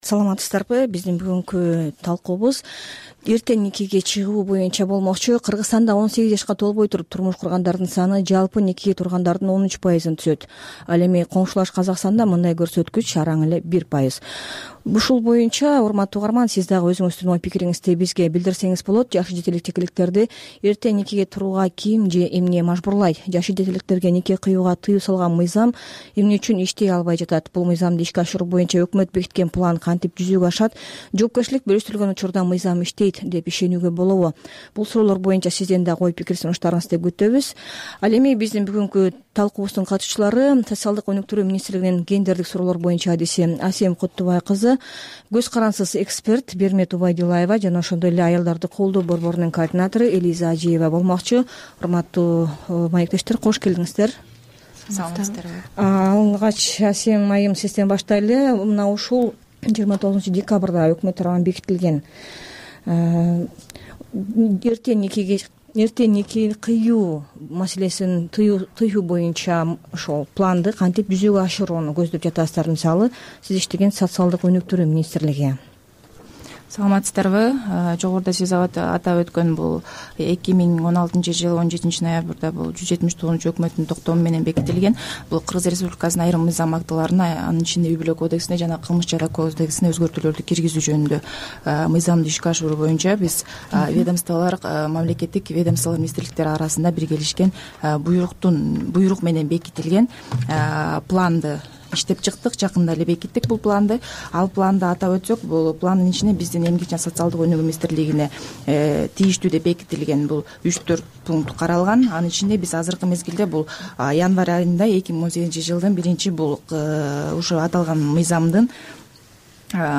Кыздардын эрте турмушка чыгып жатканына эмне себеп? Бул маселе "Азаттык" радиосунда "Арай көз чарай" берүүсүндө талкууланды.